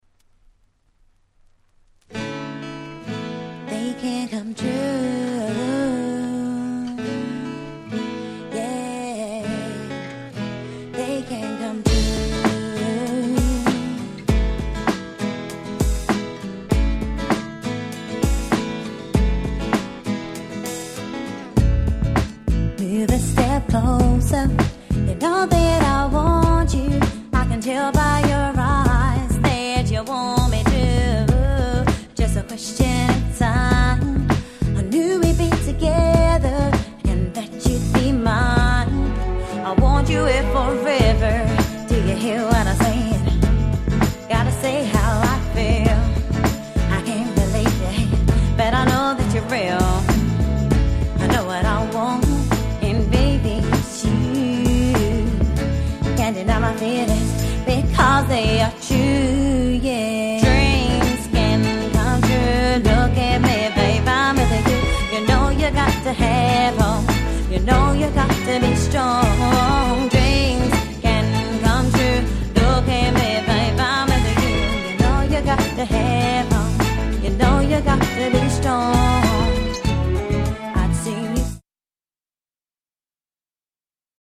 UK R&B Classics !!